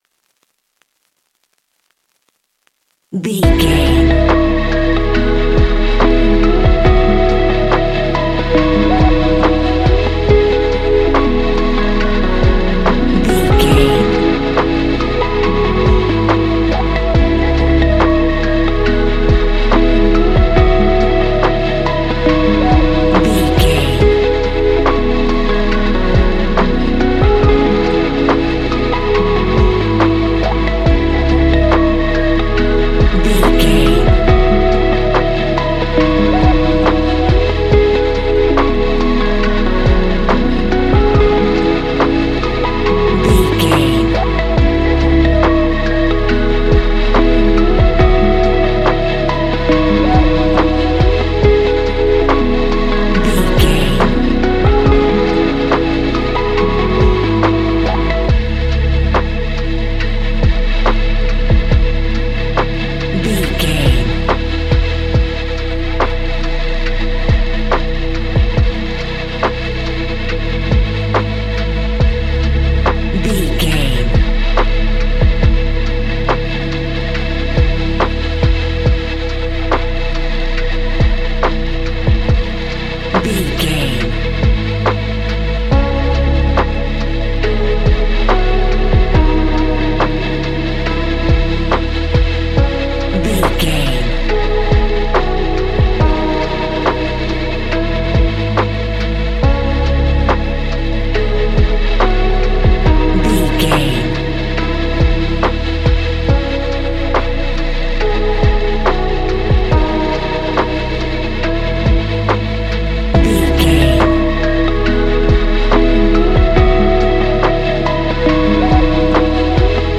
Ionian/Major
C♯
chilled
laid back
Lounge
sparse
new age
chilled electronica
ambient
atmospheric
morphing
instrumentals